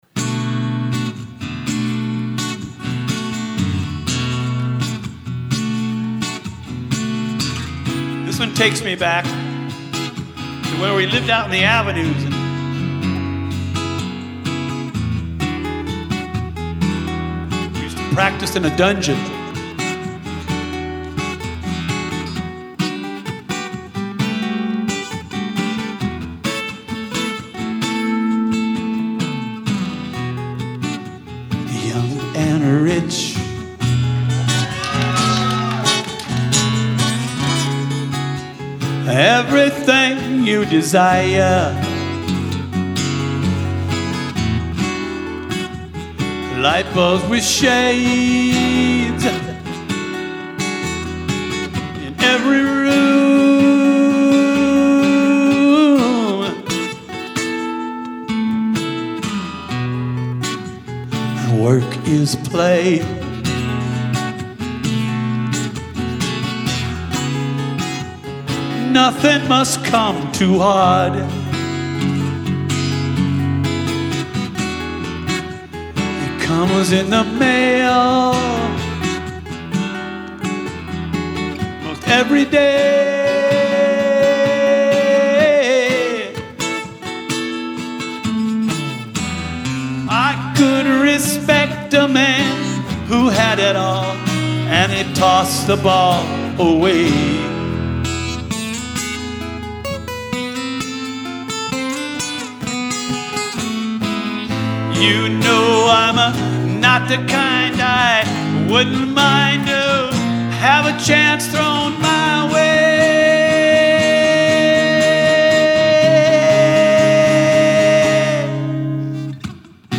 at the Rio Theatre in Santa Cruz